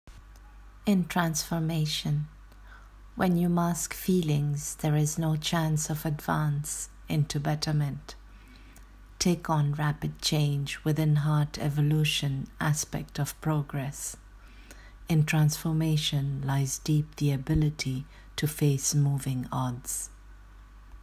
Reading of the poem: